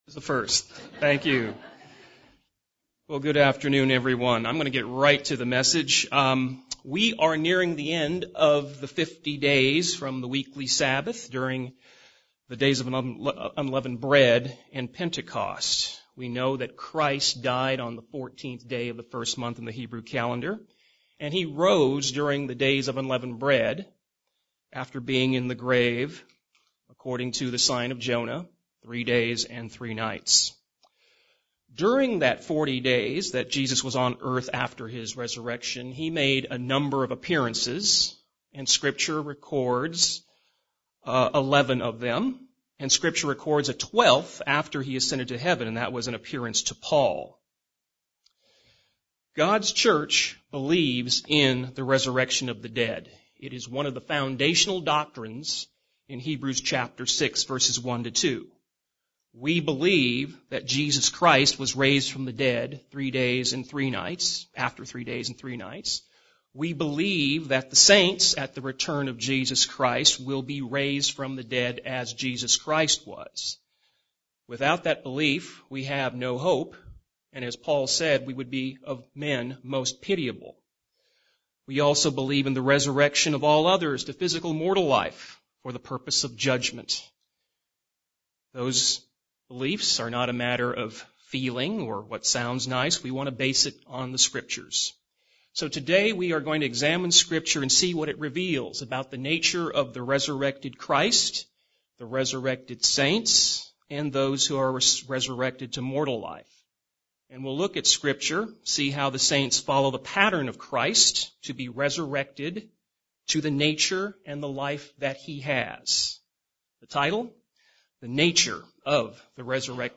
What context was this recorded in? Given in San Diego, CA